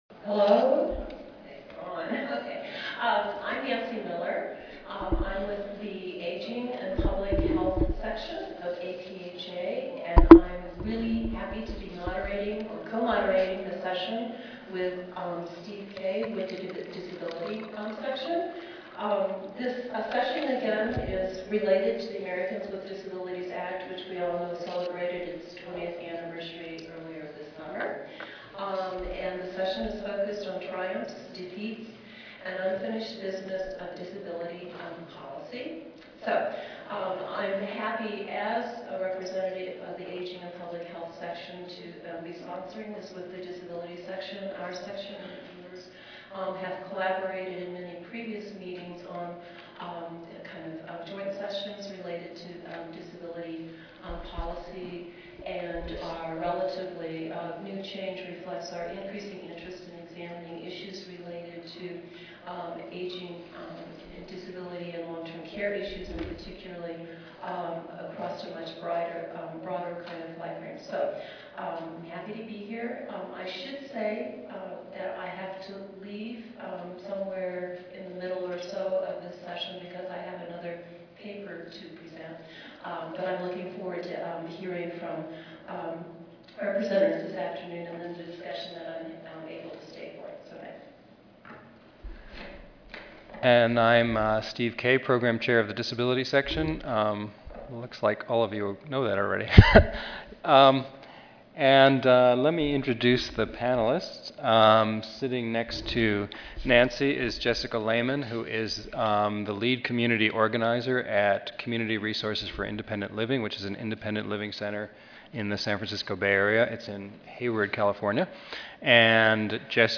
Triumphs, defeats, and unfinished business of disability policy: Disability & Gerontological Health Section joint session